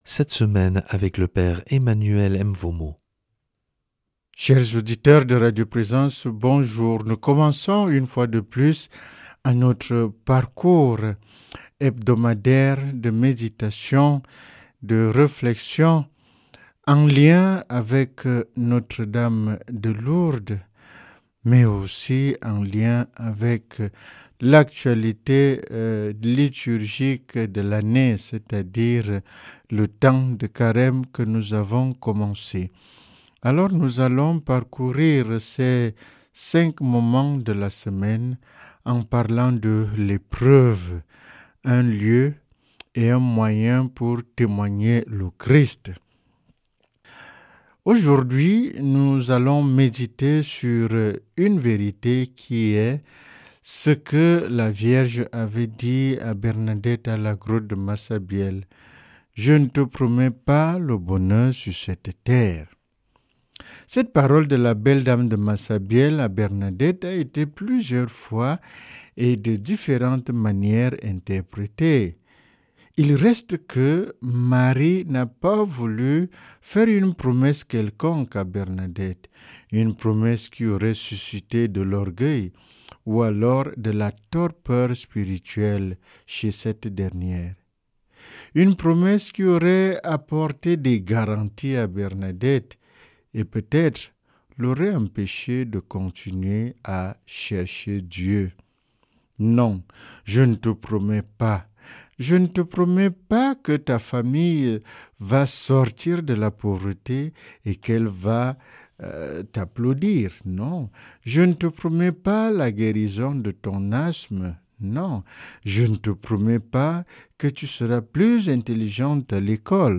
lundi 2 mars 2026 Enseignement Marial Durée 10 min